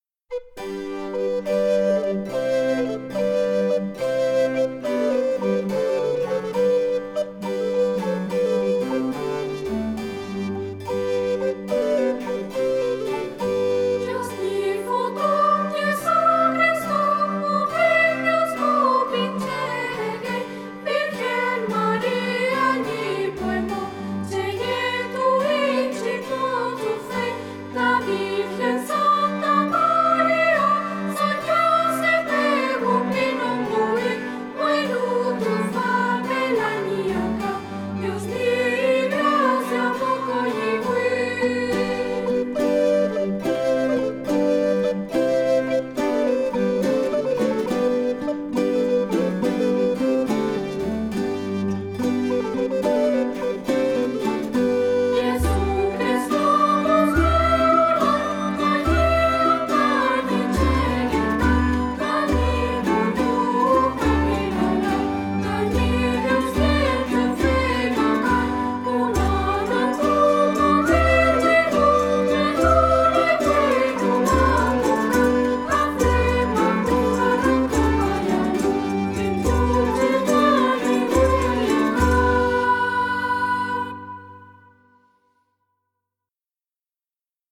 Música religiosa
Música vocal